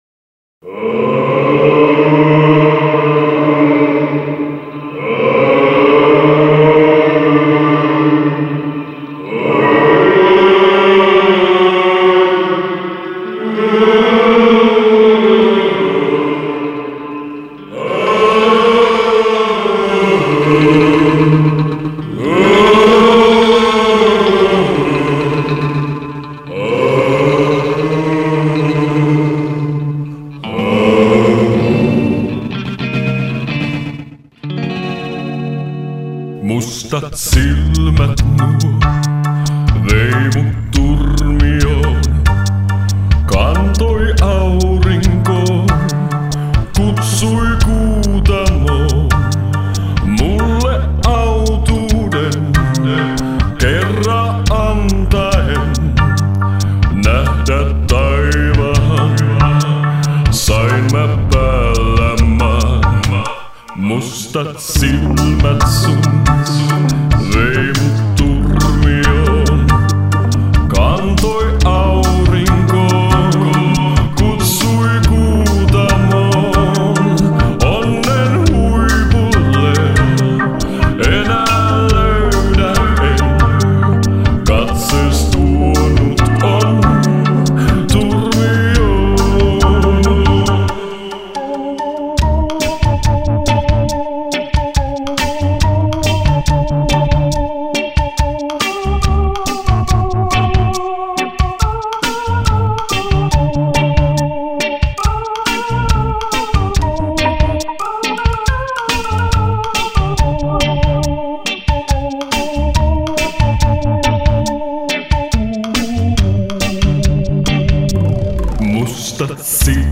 iskelmälaulanta
kitarat, ohjelmointi
basso
urku